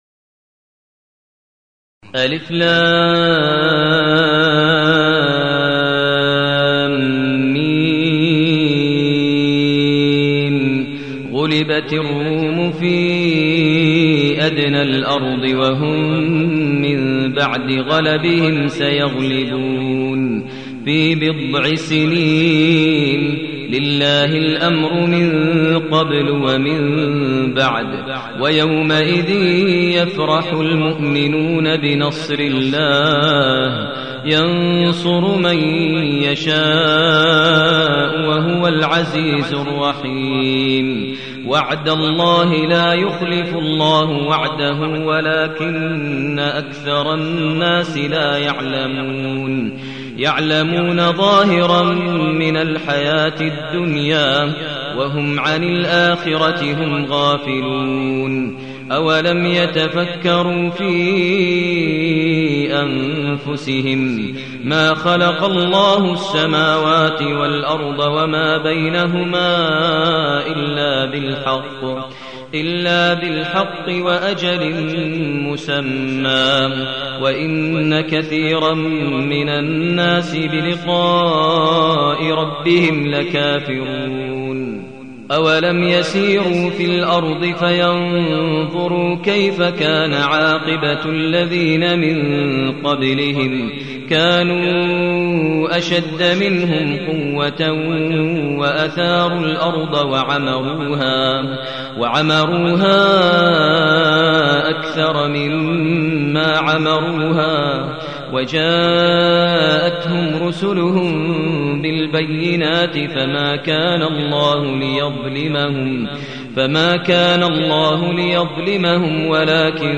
المكان: المسجد النبوي الشيخ: فضيلة الشيخ ماهر المعيقلي فضيلة الشيخ ماهر المعيقلي الروم